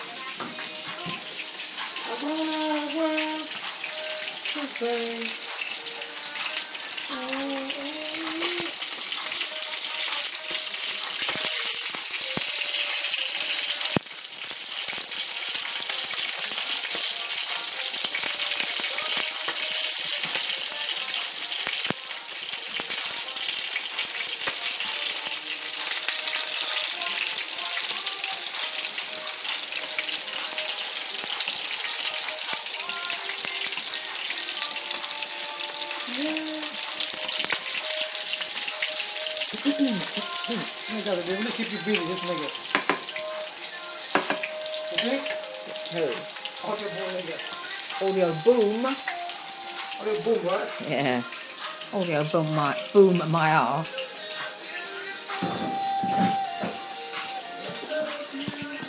Chicken frying soundscape